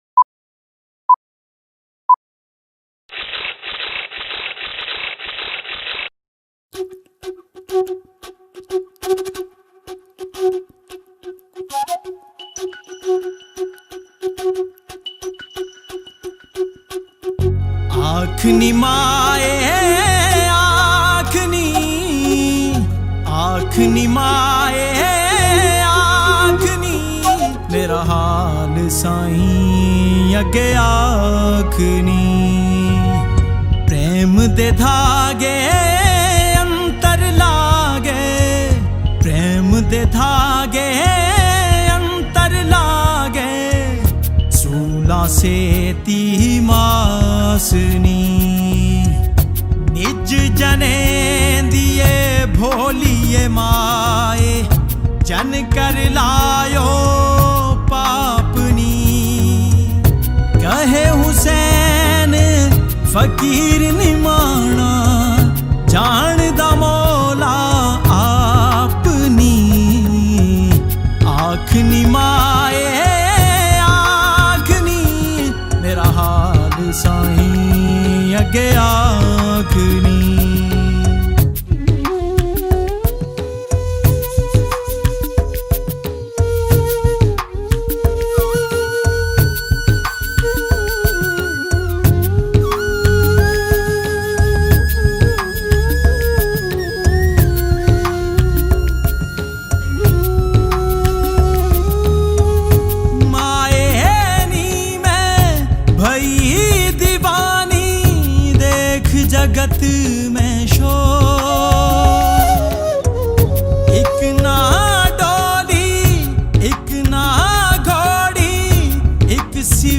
Sufi Songs
Punjabi Kalam